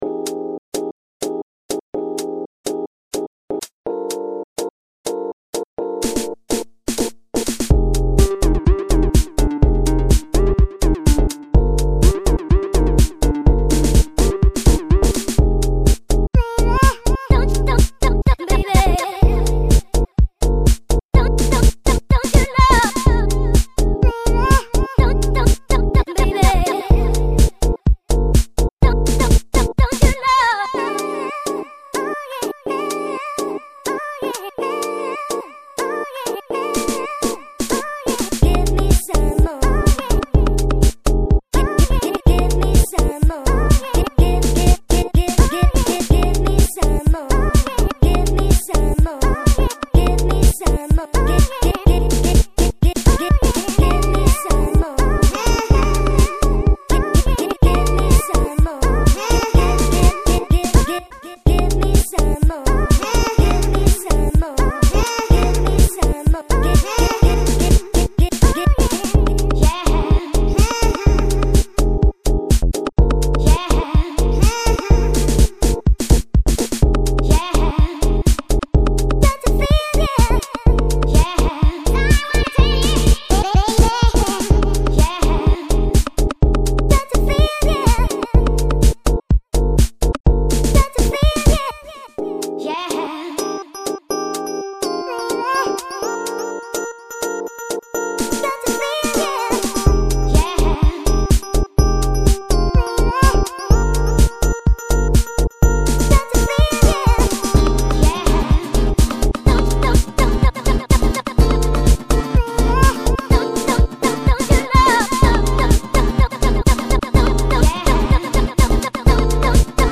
Vos Compos House
note : je n'ai utlisé aucun effet, exepté l'echo natif de Makingwaves, aucun vsti non plus.
J'aime beaucoup le côté kitch des voix. De la bonne vieille house qui sonne comme il se doit, avec une couleur perso.
Frais; simple et amusant..
Les voix me rapellent celles des morceaux de "speed garage"....
PS /Je trouve certains sons rythmiques un peu vieillots; mais tu l'as peut être fais exprès.